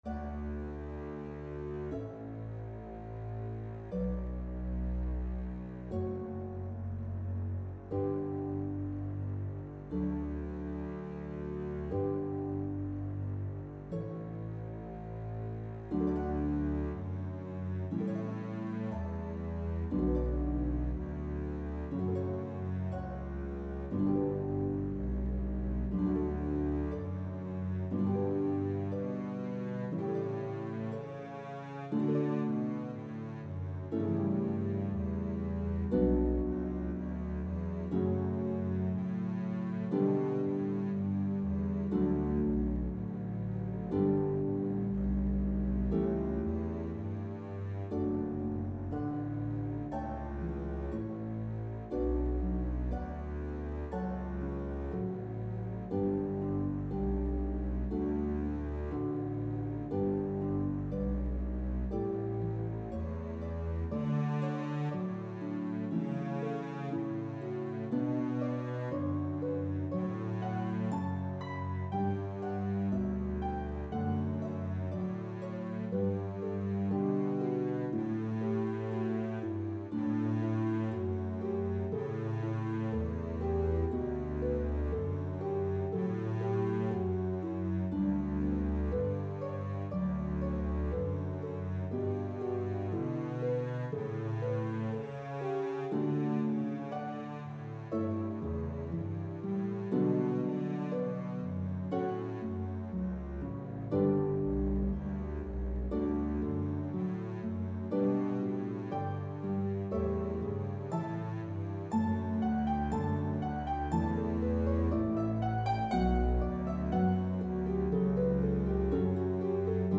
Harp and Contrabass version